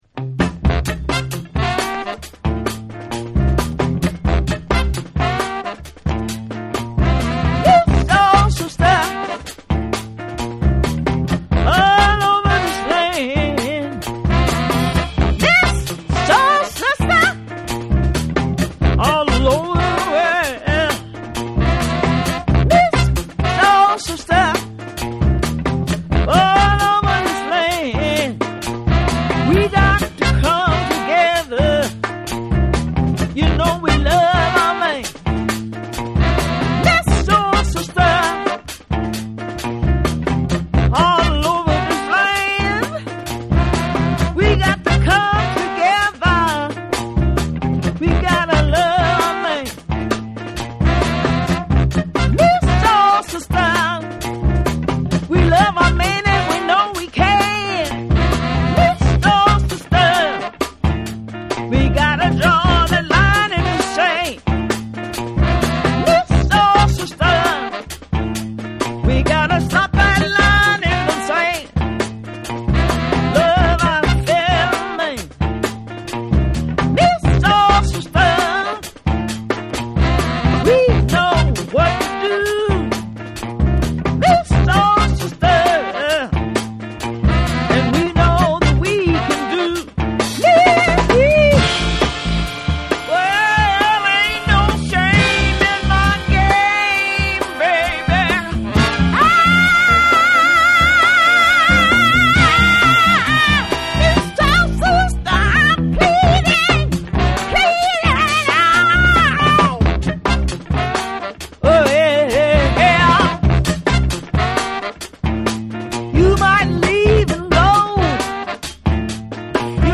タイト/グルーヴィーなリズムに、ファンキーなギター・リフやダイナミックなホーン